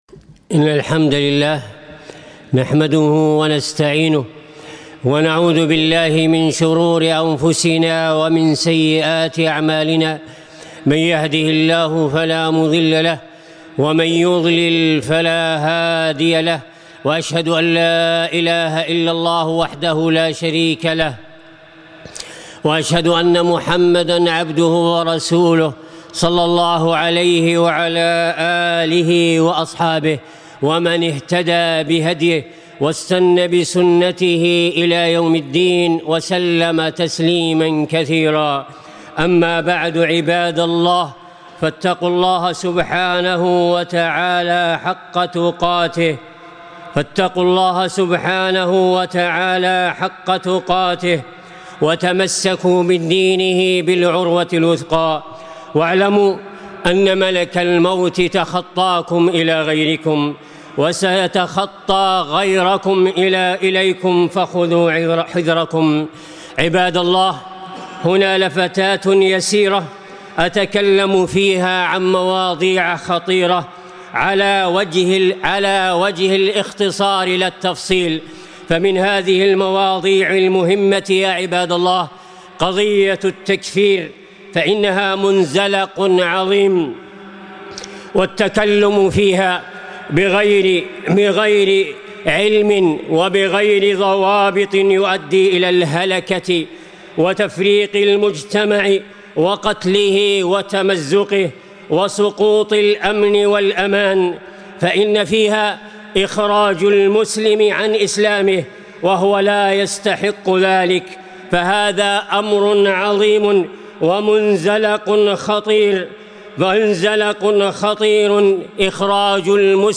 خطبة - قضايا مهمة للأمة